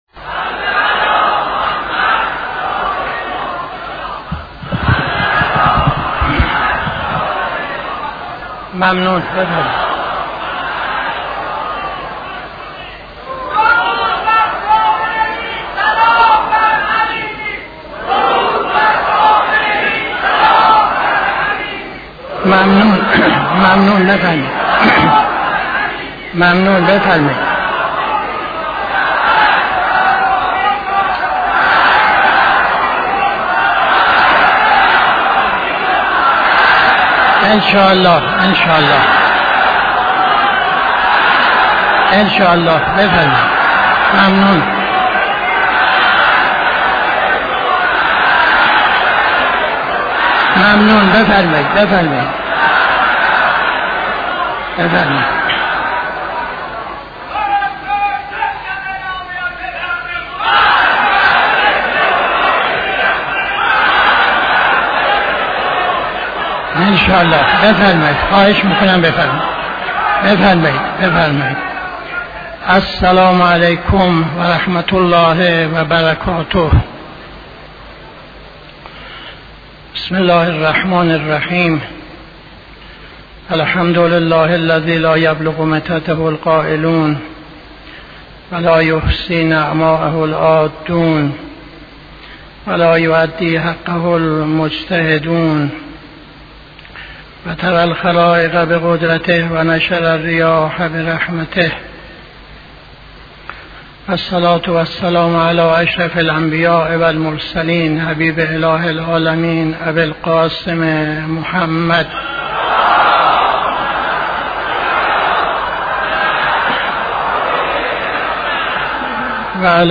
خطبه اول نماز جمعه 13-02-81